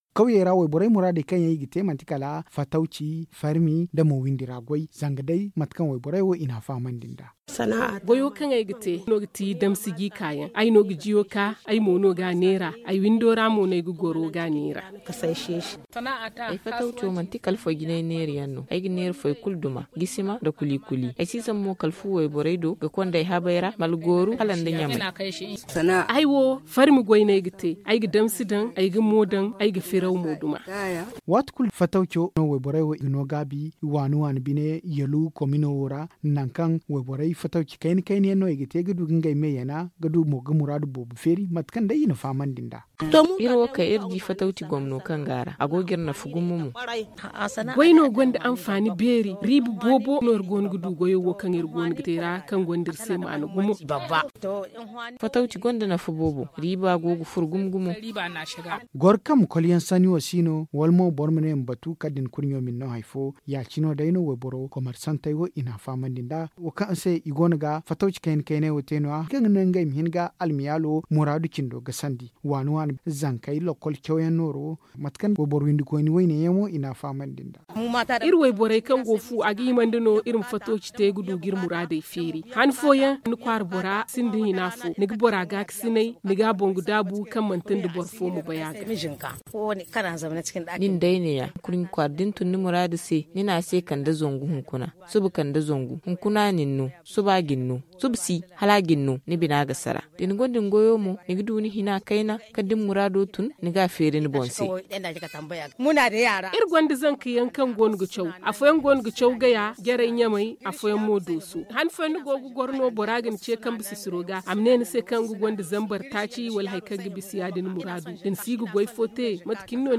s’est entretenu avec quelques femmes, voici son reportage.
Le magazine en zarma